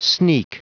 Prononciation du mot sneak en anglais (fichier audio)
Prononciation du mot : sneak